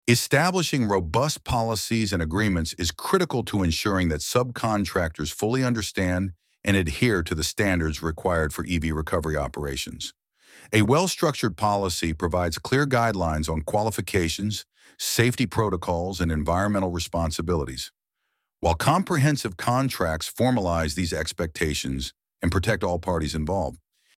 ElevenLabs_Topic_1.4.5.1.mp3